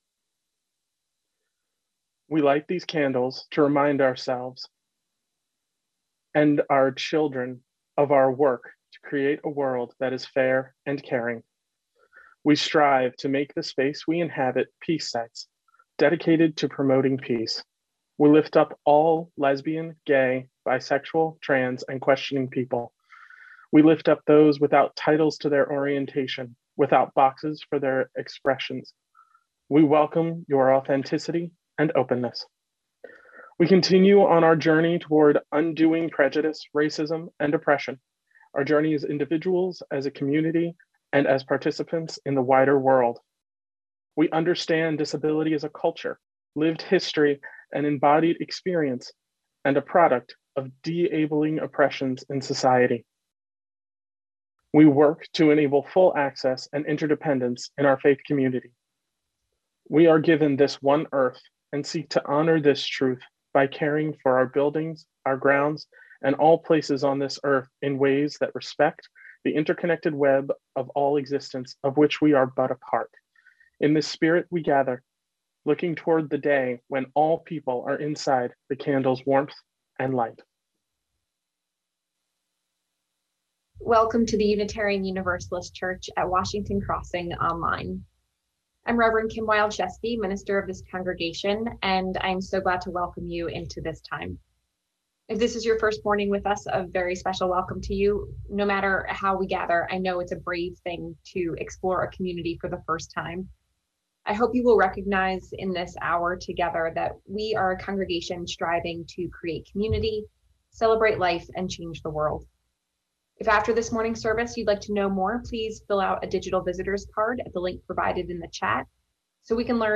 Sunday-Service-April-4-2021-Final